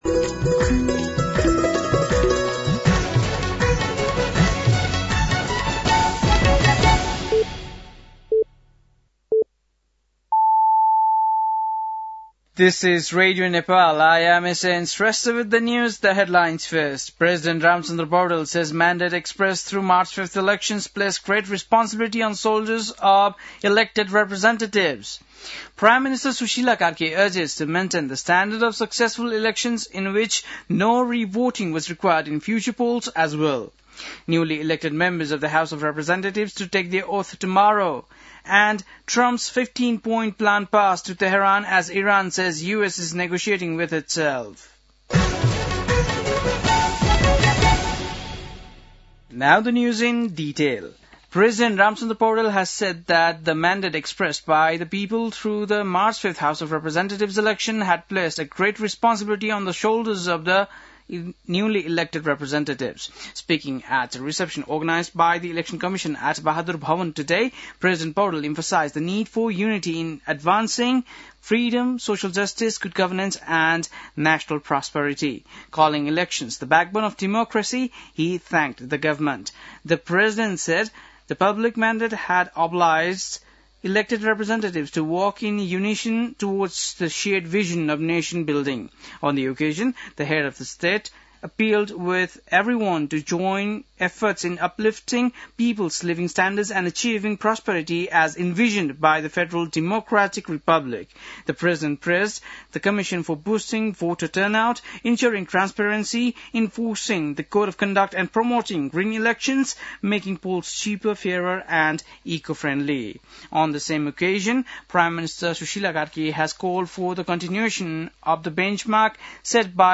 बेलुकी ८ बजेको अङ्ग्रेजी समाचार : ११ चैत , २०८२